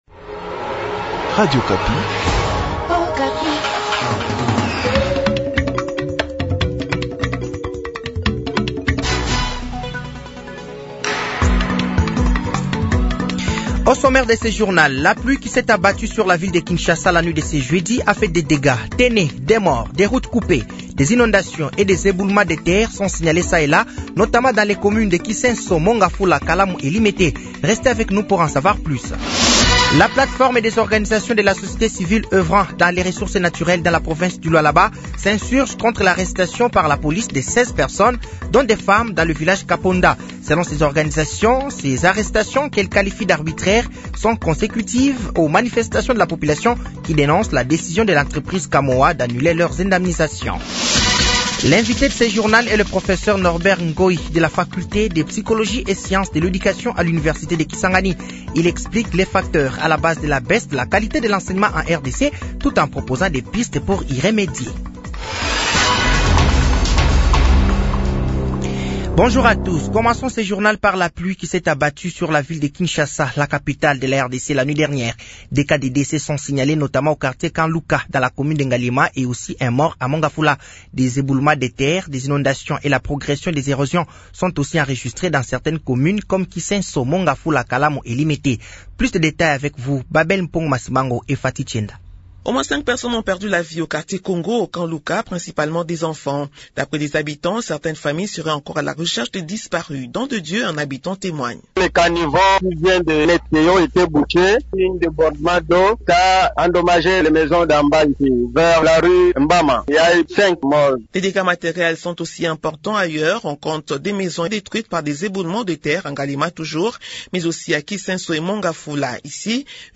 Journal français de 15h de ce vendredi 02 mai 2025